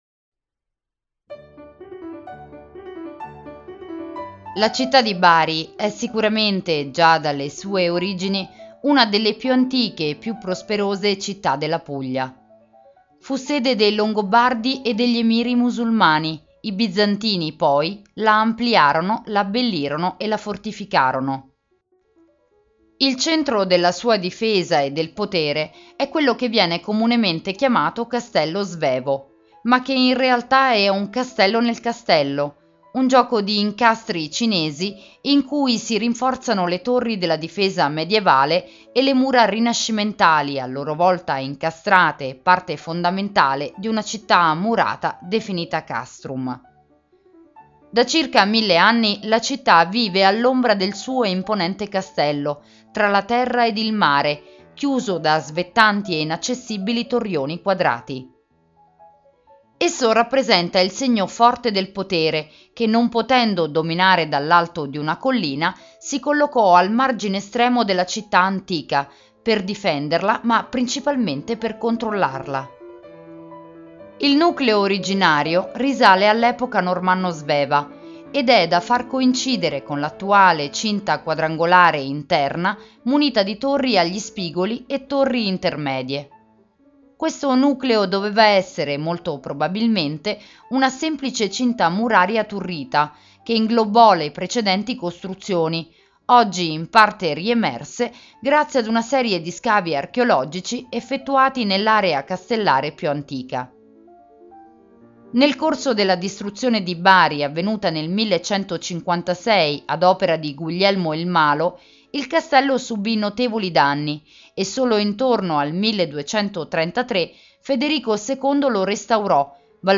Audioguida Bari - Il Castello - Audiocittà